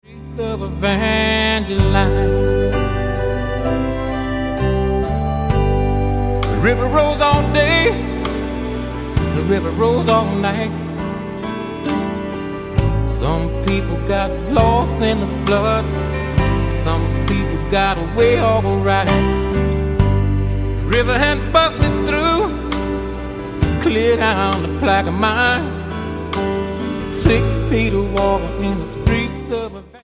Genre: Pop Rock
lush, rich sounds
guitar